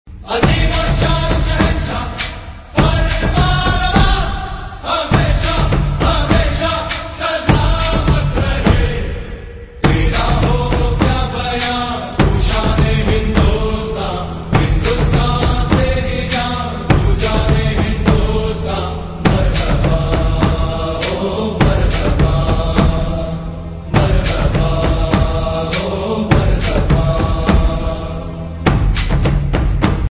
Category: Bollywood & Indian